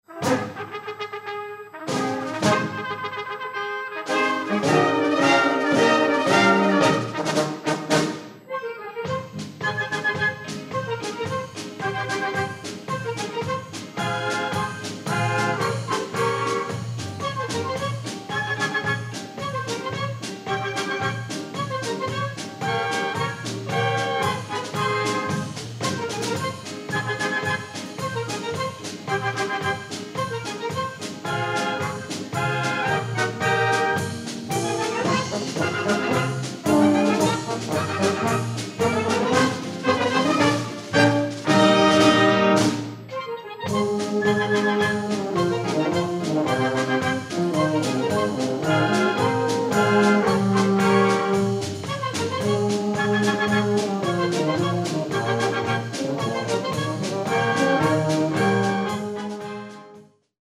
Konzert 2006 -Download-Bereich
-------Das Orchester-------